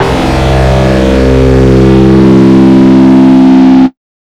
NYC125SYNT-L.wav